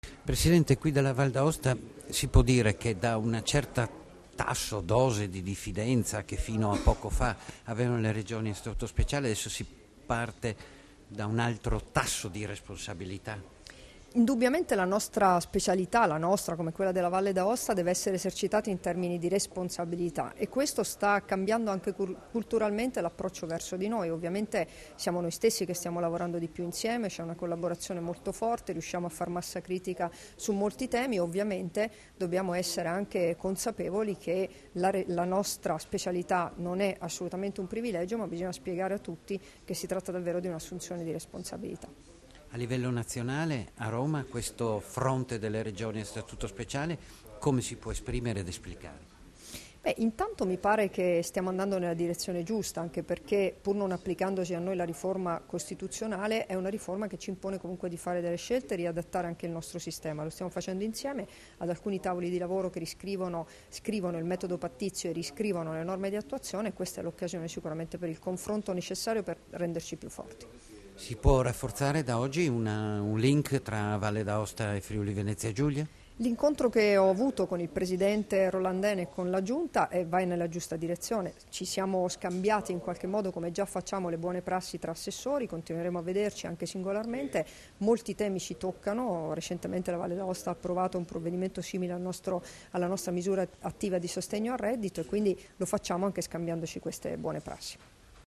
Dichiarazioni di Debora Serracchiani (Formato MP3) [1572KB]
a margine dell'incontro con il presidente della Valle d'Aosta Augusto Rollandin, rilasciate ad Aosta il 30 gennaio 2016